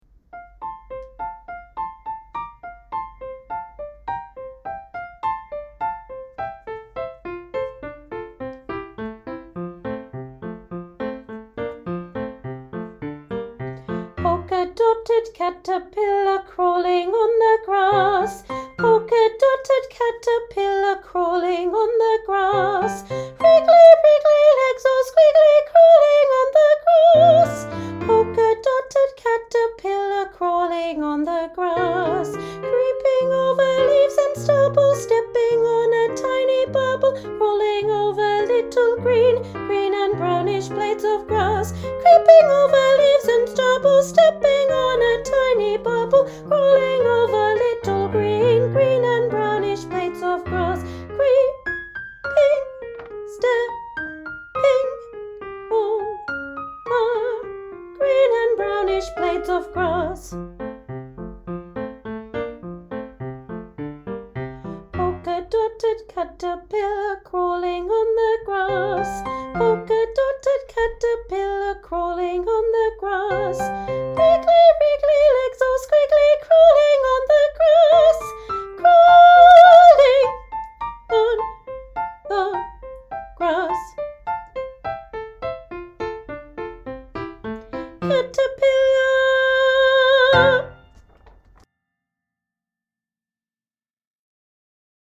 Oh Spring, The Caterpillar Jnr Part 1 Slow Tempo